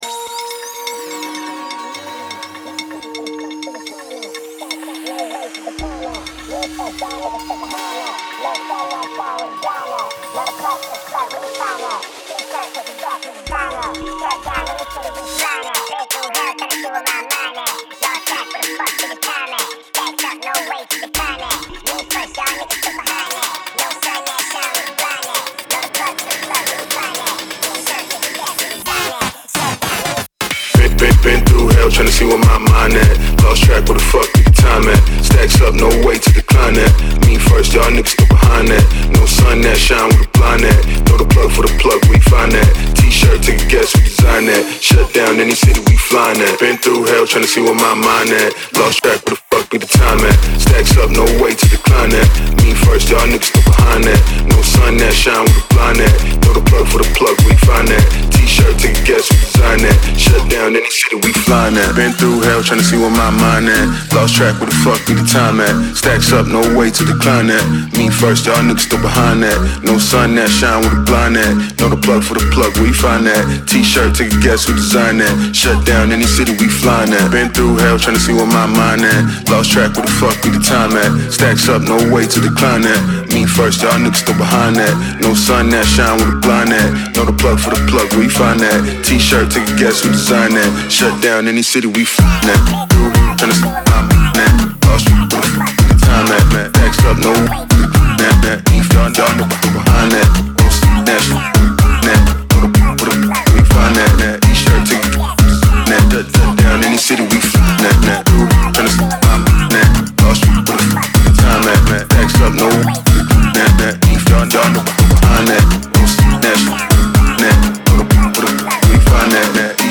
BPM125-125
Audio QualityPerfect (High Quality)
Rap / Electro song for StepMania, ITGmania, Project Outfox
Full Length Song (not arcade length cut)